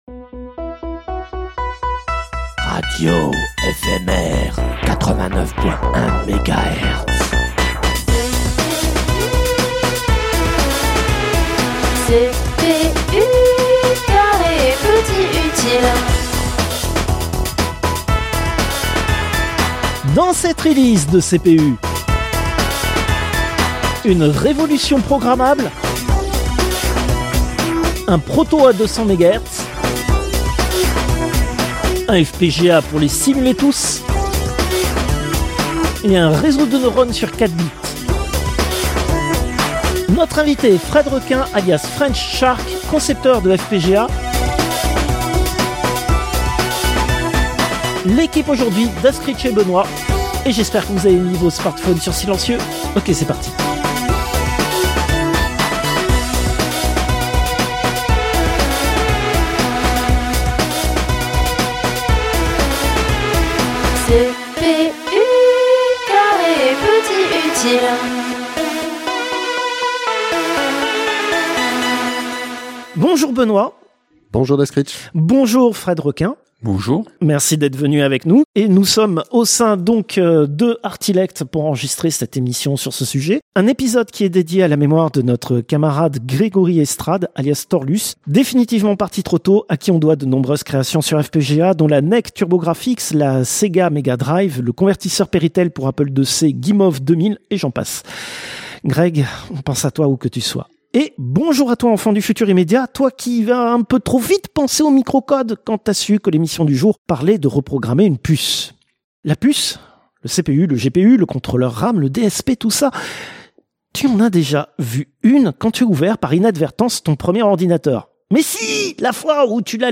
Chief Place’n’Route Officer L'interview a été enregistrée en Octobre 2025 au sein du Fablab Artilect avec l'aide de l'association Silicium pour leur soutien logistique.